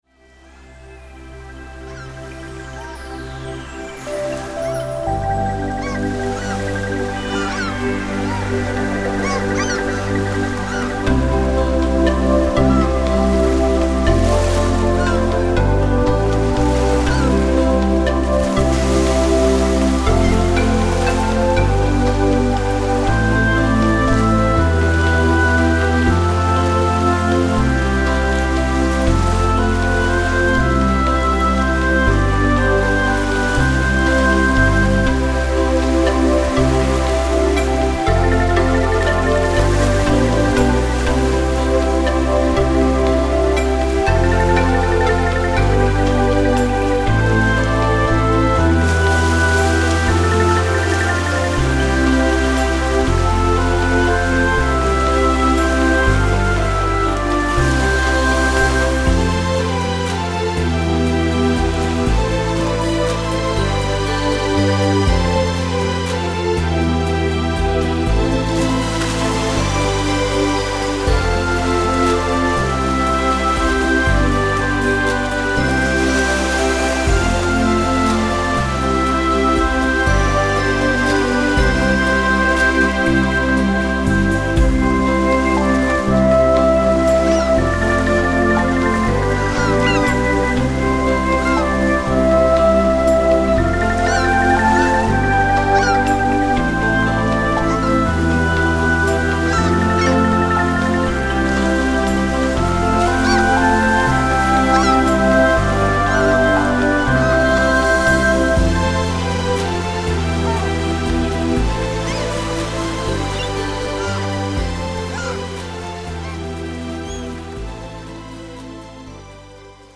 Entspannungsmusik für Kinder